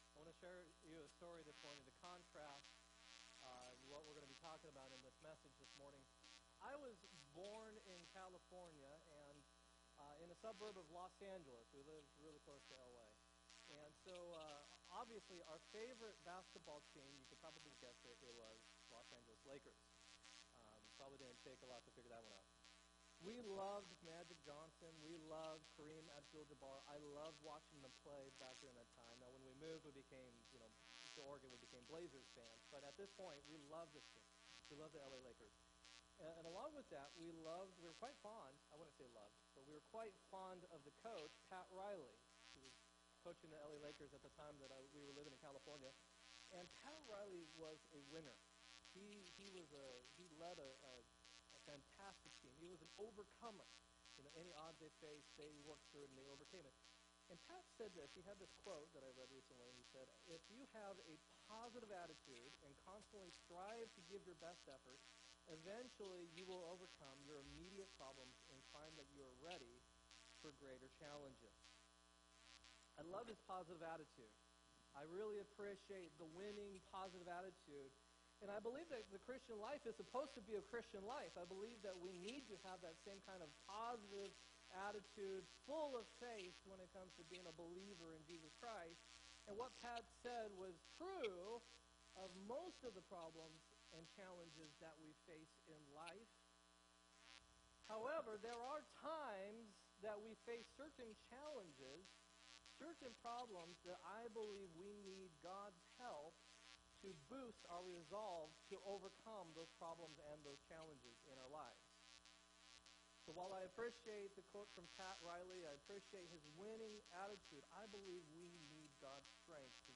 4-1-17 sermon